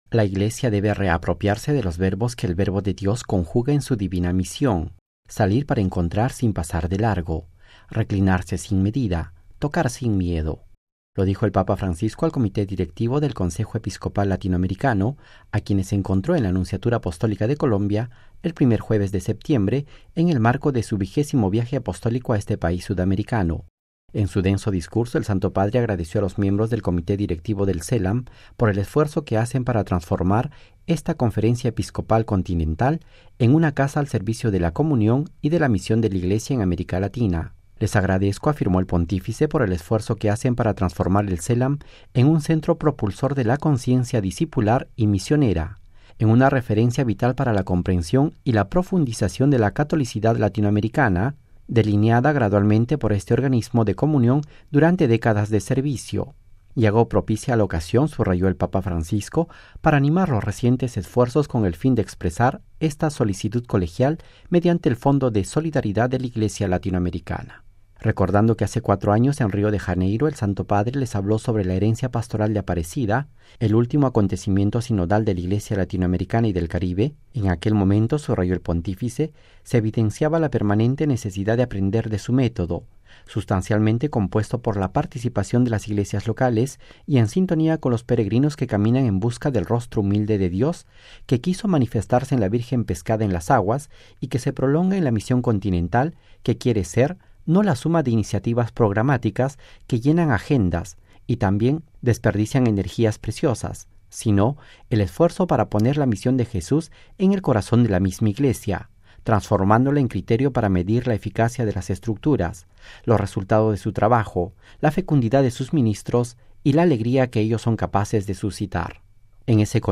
(RV).- “La Iglesia debe reapropiarse de los verbos que el Verbo de Dios conjuga en su divina misión. Salir para encontrar, sin pasar de largo; reclinarse sin desidia; tocar sin miedo”, lo dijo el Papa Francisco al Comité Directivo del Consejo Episcopal Latinoamericano (CELAM), a quienes encontró en la Nunciatura Apostólica de Colombia, el primer jueves de septiembre, en el marco de su 20° Viaje Apostólico a este país sudamericano.
Voz y texto completo del discurso del Papa Francisco